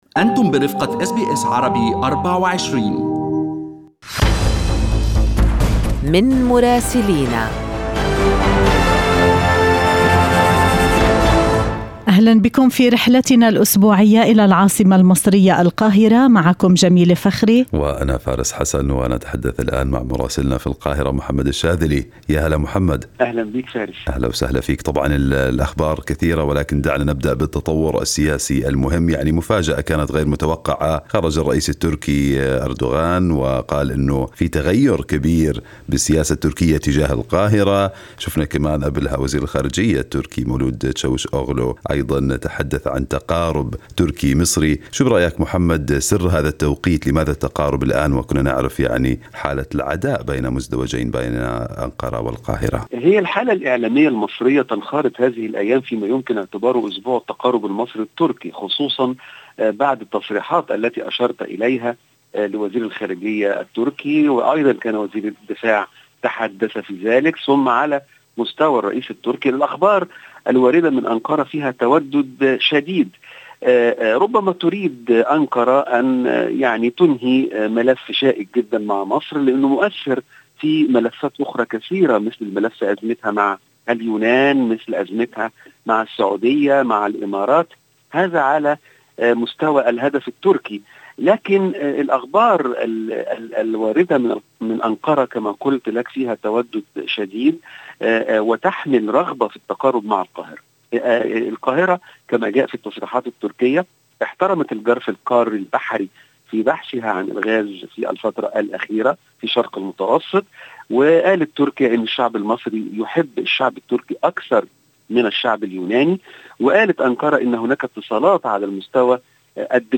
يمكنكم الاستماع إلى تقرير مراسلنا في القاهرة بالضغط على التسجيل الصوتي أعلاه.